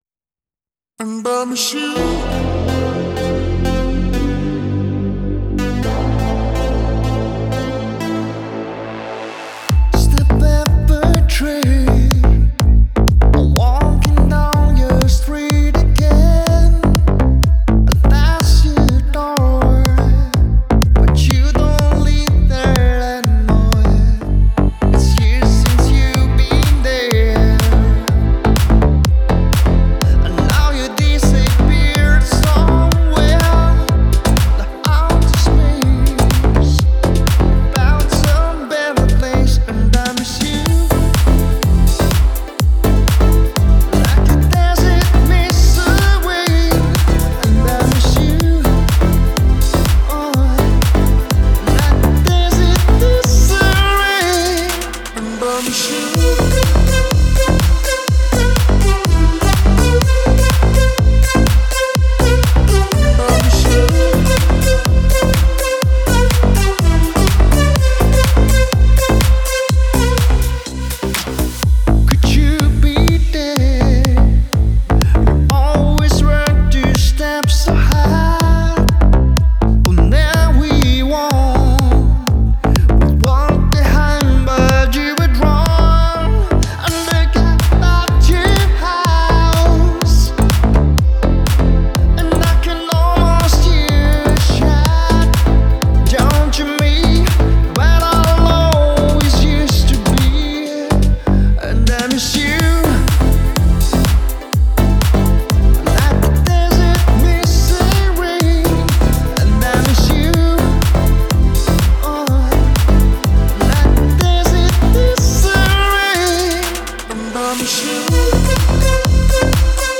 это трек в жанре электронная музыка